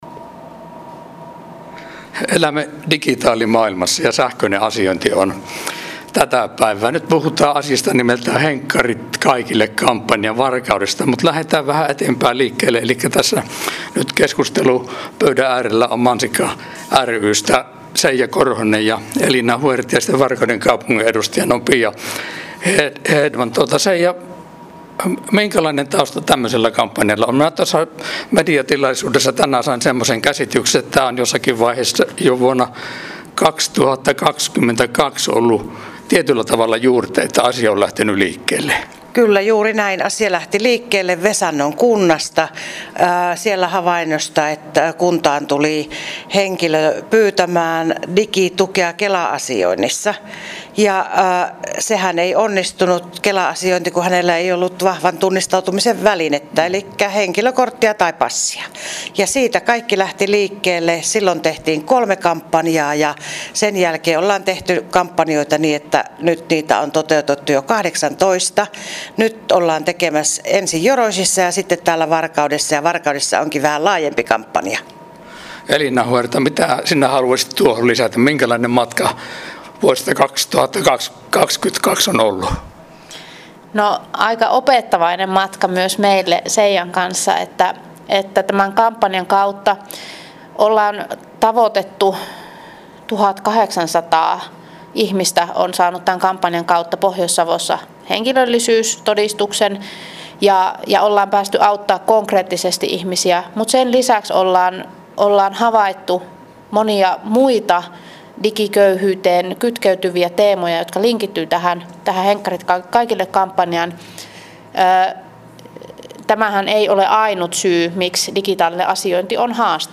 Äänihaastattelussa